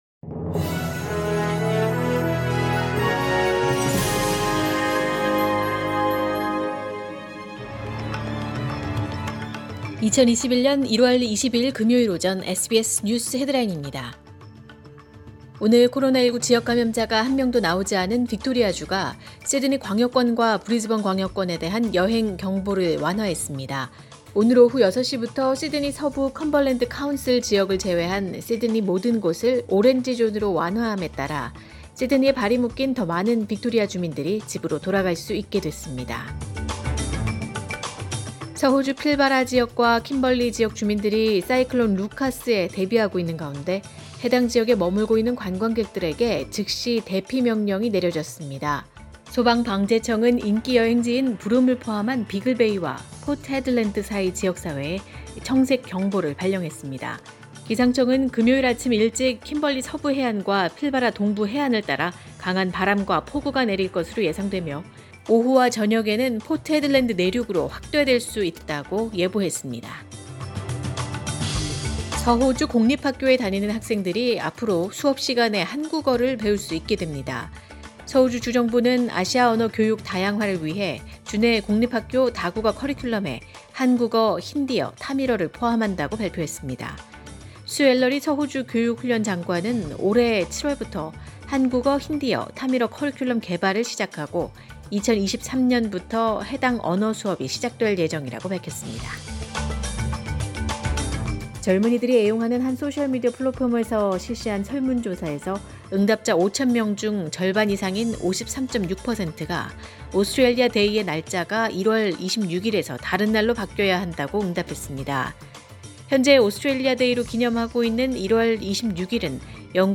2021년 1월 22일 금요일 오전의 SBS 뉴스 헤드라인입니다.